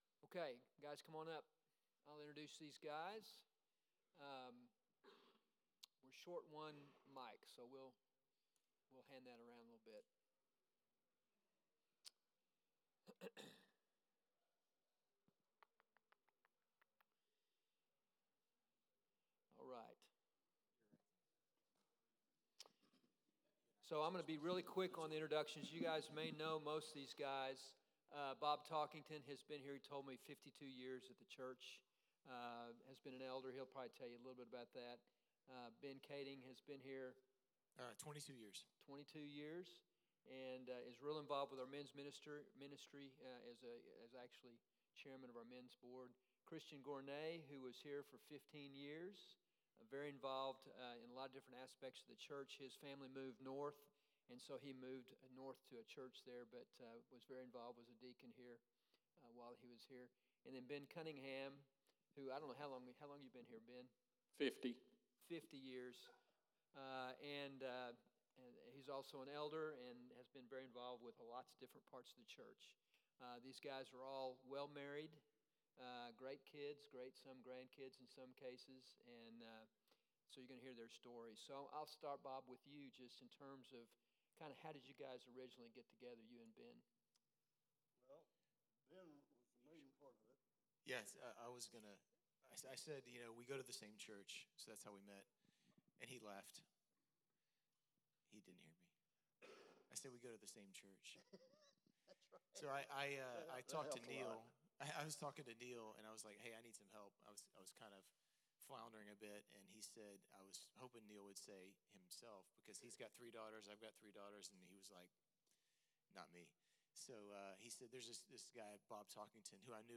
Men’s Breakfast | Mentoring Panel Discussion